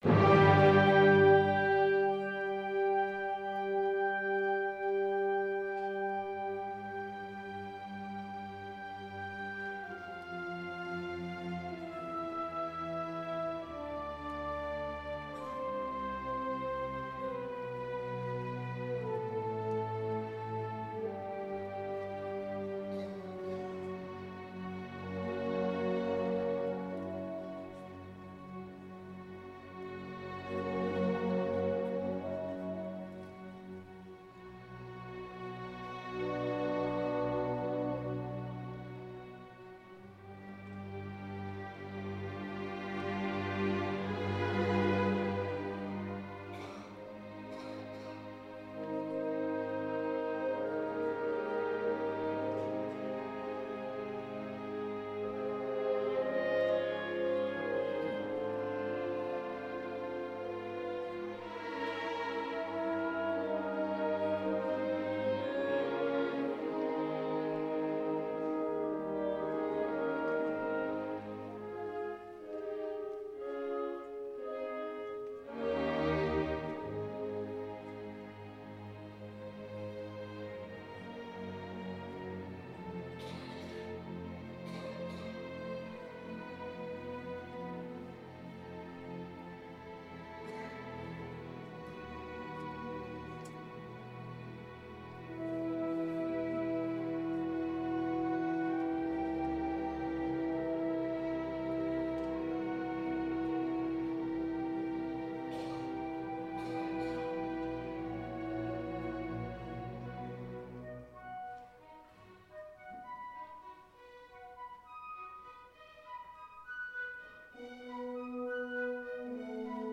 Orchestra version
Style: Classical